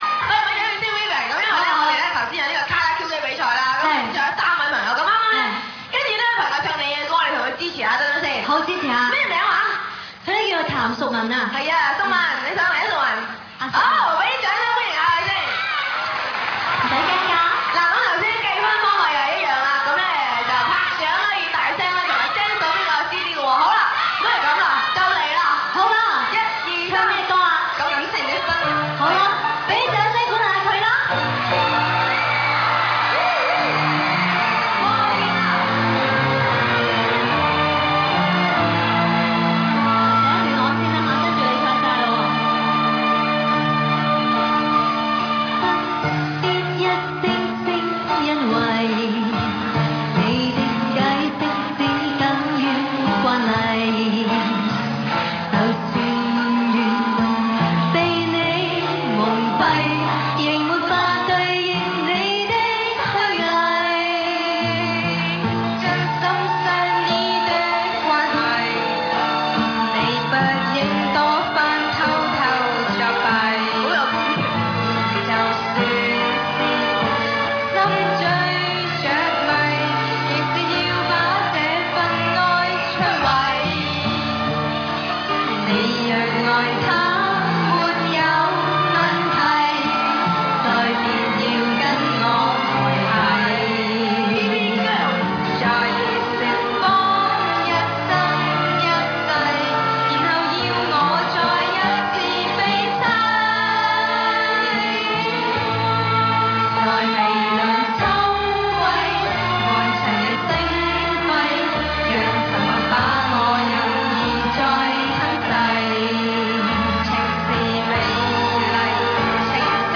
LIVE
5. Singing contest - Vivian and fan singing "Wedding of Feelings"